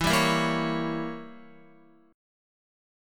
Edim7 chord